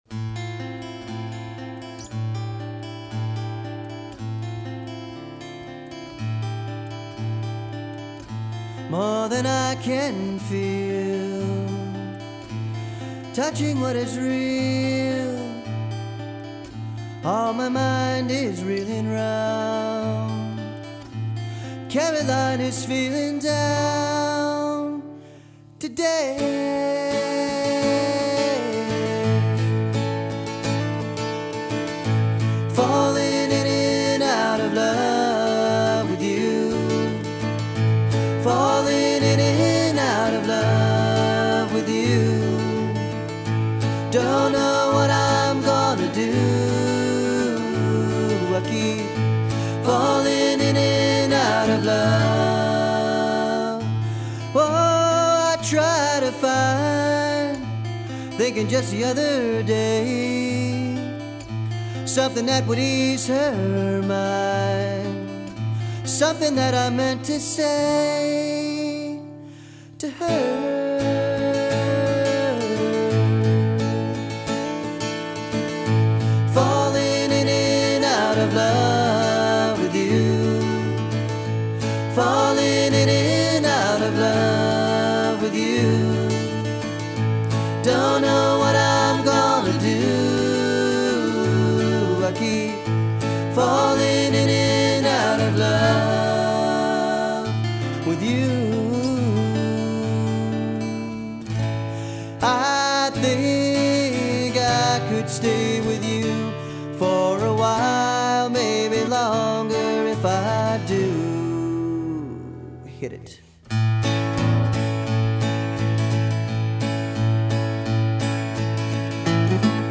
lead guitar
violin